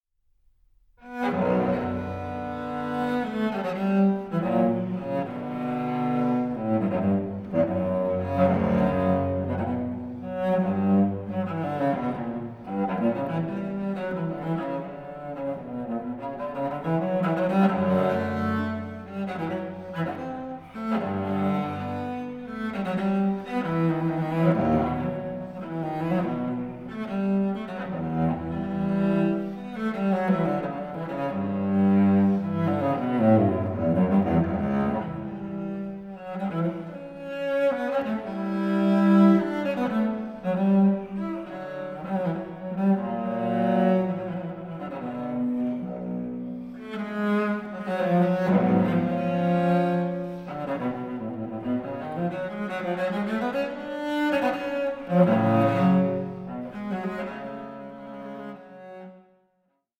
baroque cello and cello piccolo